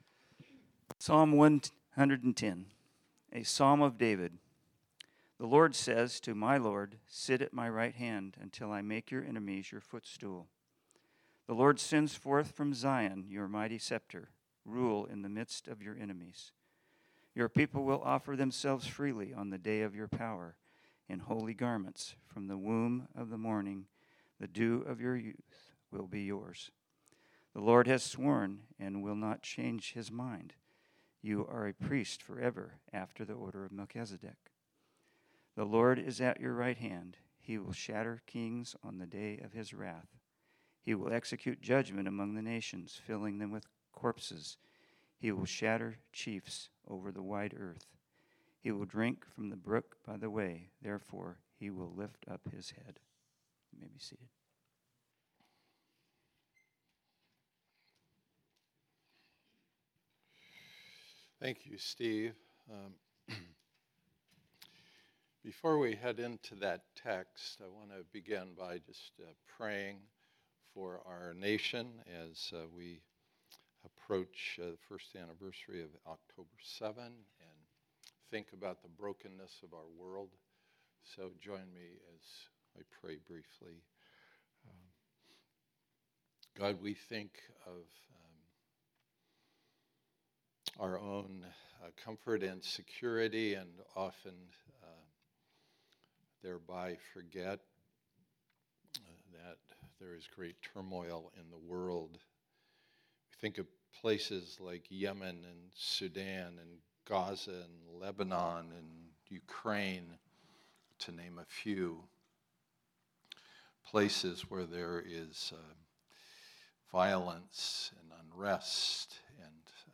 Sermons Our Hope is in the Lord